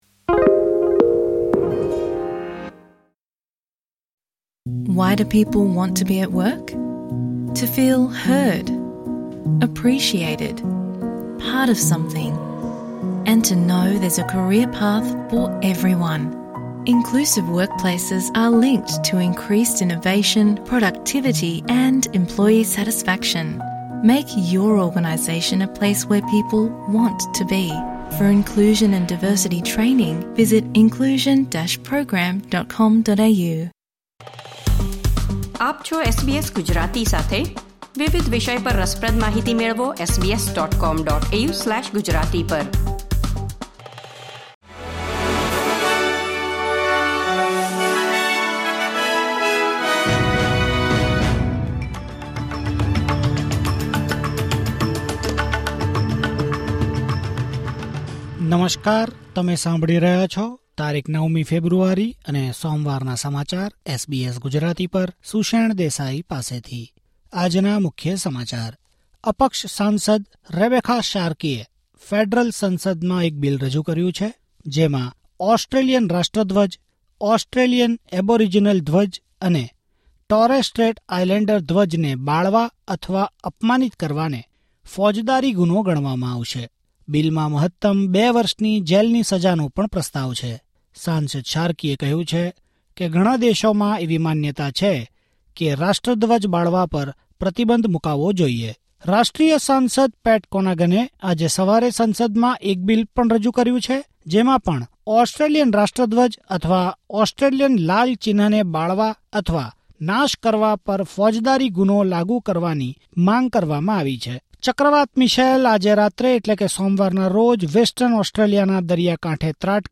Listen to the latest Australian news from SBS Gujarati